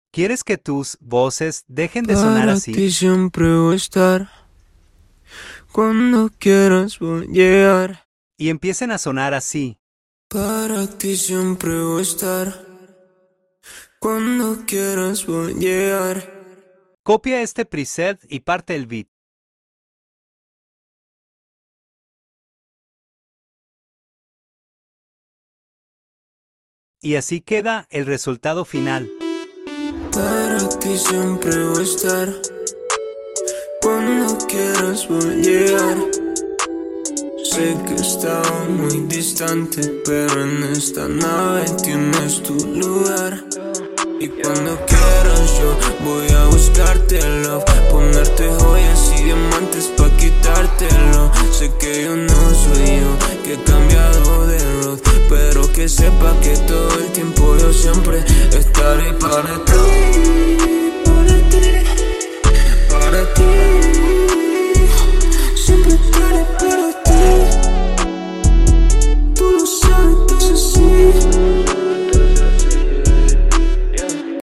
El mejor PRESET para mejorar tus voces de plugg en BANDLAB